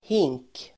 Uttal: [hing:k]